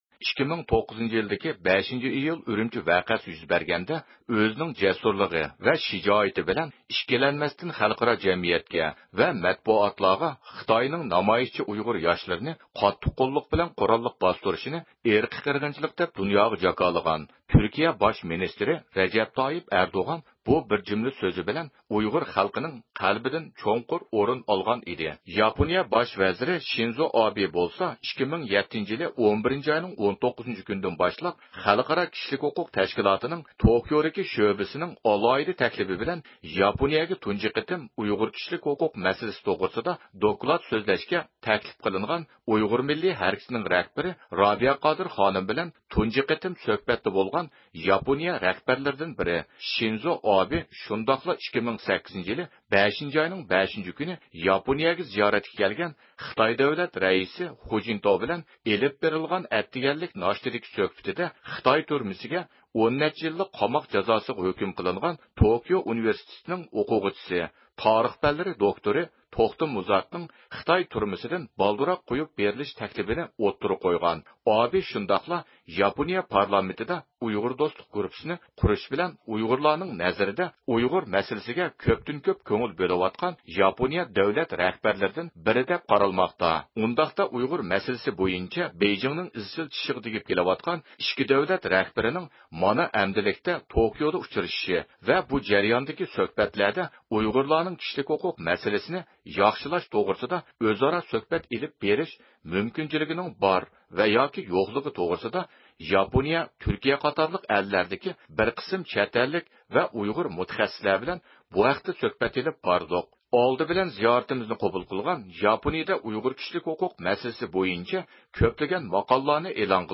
ئۇنداقتا، ئۇيغۇر مەسىلىسى بويىچە بېيجىڭنىڭ ئىزچىل چىشىغا تېگىپ كېلىۋاتقان ئىككى دۆلەت رەھبىرىنىڭ مانا ئەمدىلىكتە توكيودا ئۇچرىشىشى ۋە بۇ جەرياندىكى سۆھبەتلەردە ئۇيغۇرلارنىڭ كىشىلىك ھوقۇق مەسىلىسىنى ياخشىلاش توغرىسىدا ئۆز-ئارا سۆھبەت ئېلىپ بېرىش مۇمكىنچىلىكىنىڭ بار ۋە ياكى يوقلۇقى توغرىسىدا ياپونىيە،تۈركىيە قاتارلىق ئەللەردىكى بىر قىسىم چەتئەللىك ۋە ئۇيغۇر مۇتەخەسسىسلەر بىلەن بۇ ھەقتە سۆھبەت ئېلىپ باردۇق.